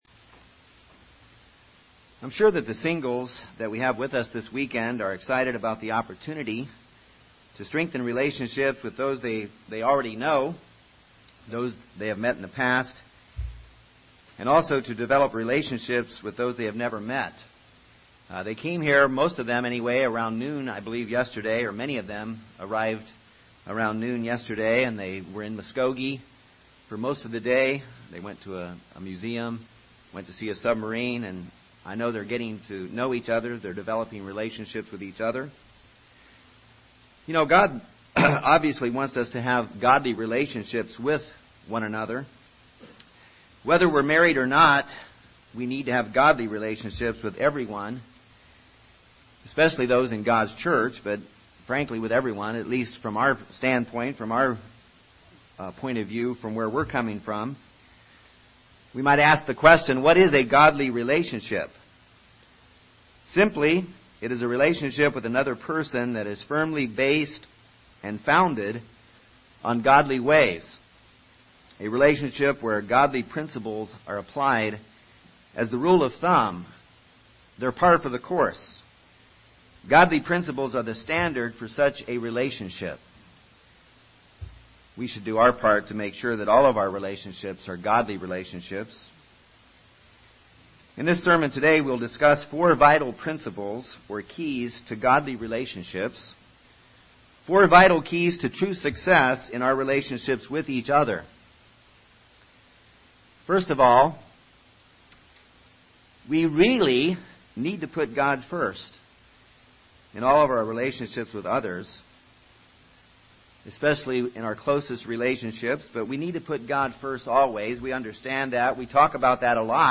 In this sermon we will discuss four vital principles or keys to Godly relationships – four vital keys to true success in our relationships with each other.